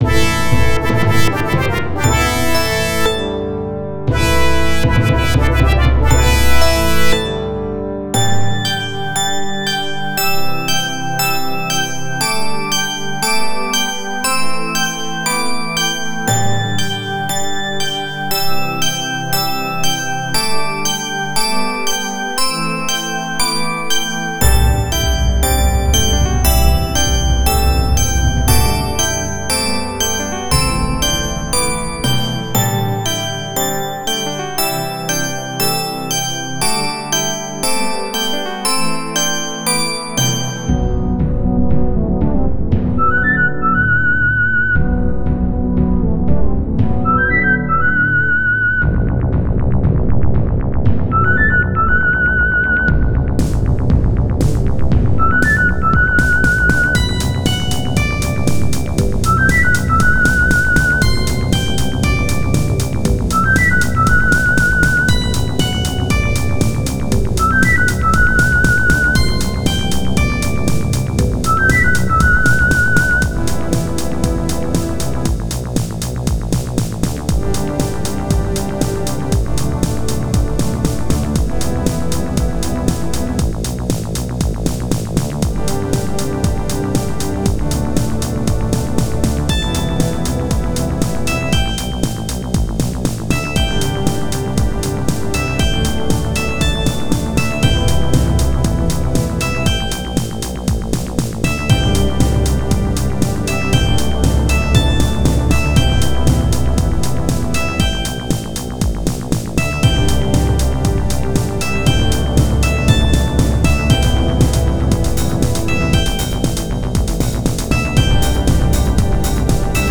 A disco-ey sort of theme. Used as title screen theme in my game for now.